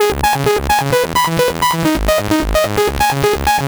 Retro School Ab 130.wav